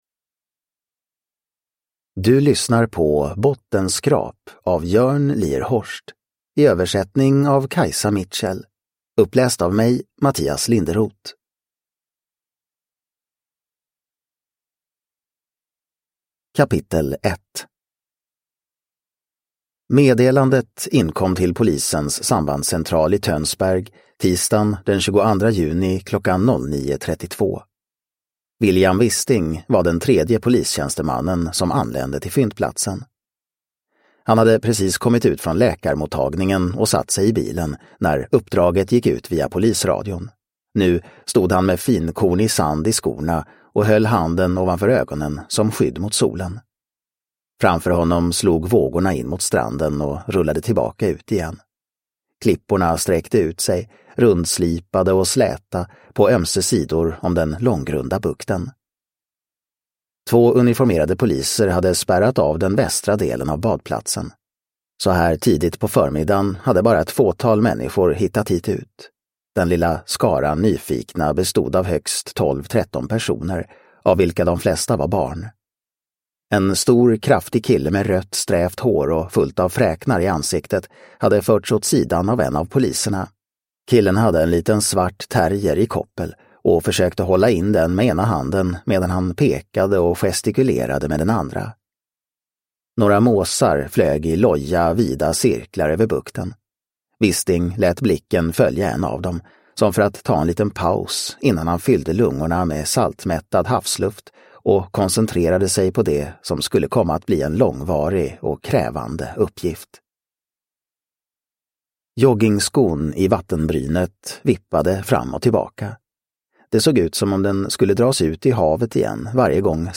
Bottenskrap – Ljudbok – Laddas ner